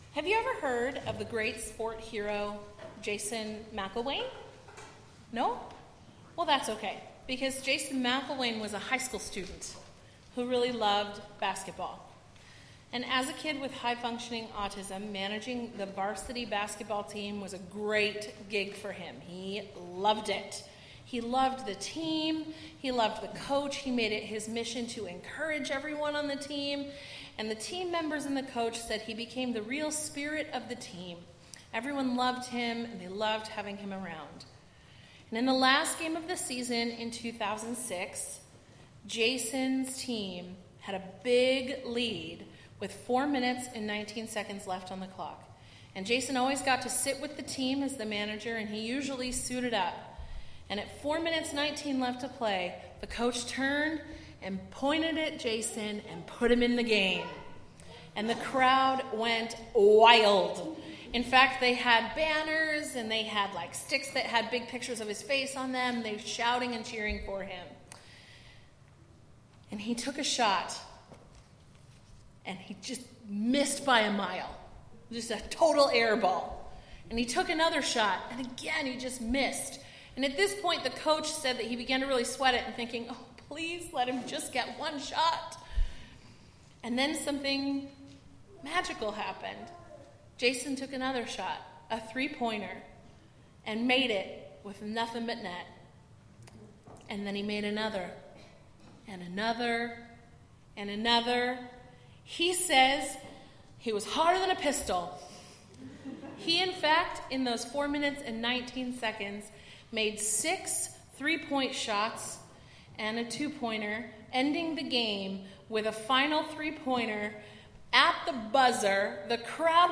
Bible Text: Luke 18:9-19:27 | Preacher